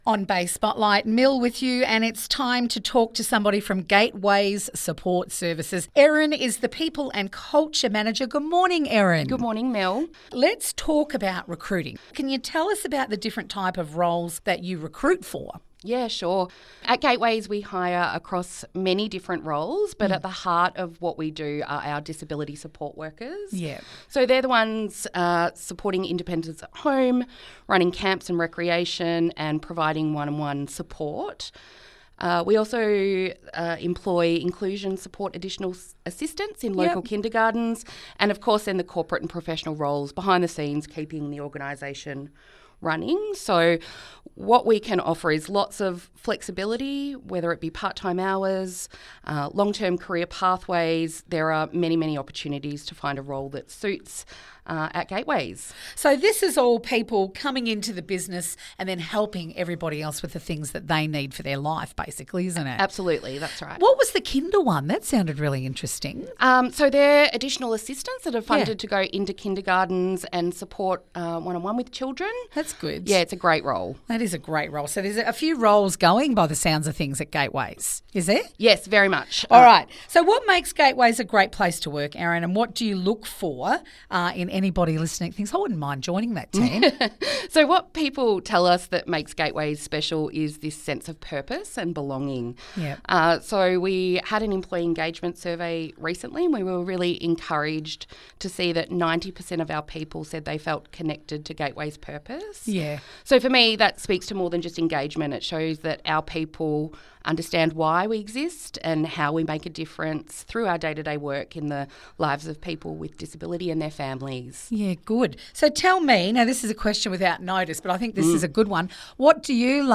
Bay Fm Interview (1)